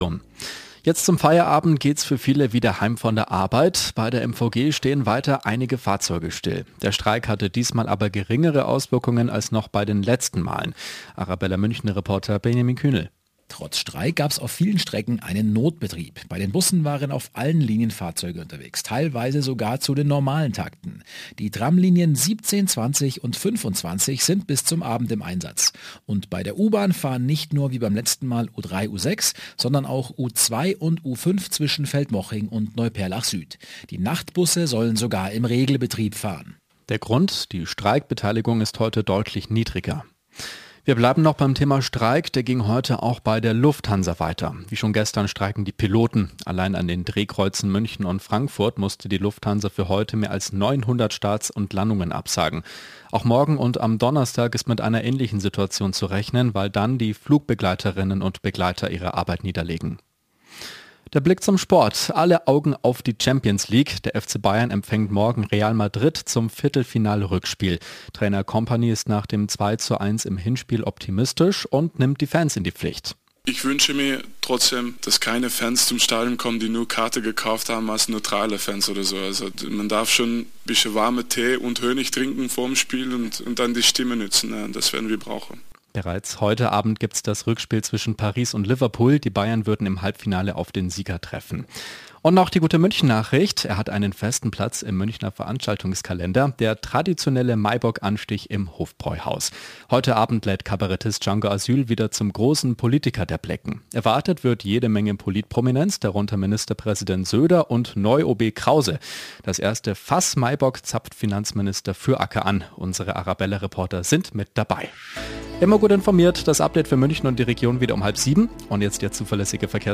Nachrichten , Nachrichten & Politik